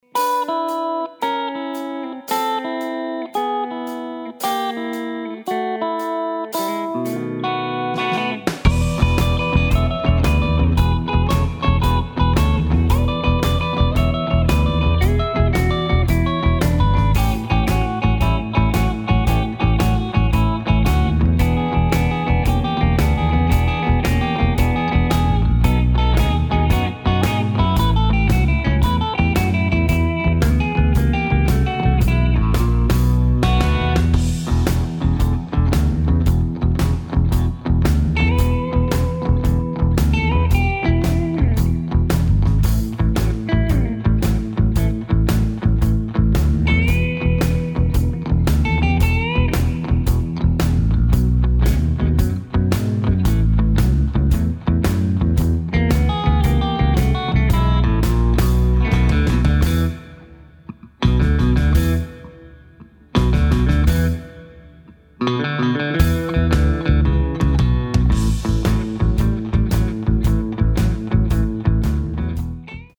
BASS GUITAR BY EAR